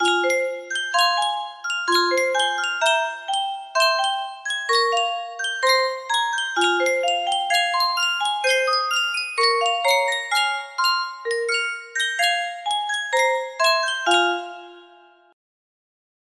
Sankyo Music Box - Katyusha KEC music box melody
Full range 60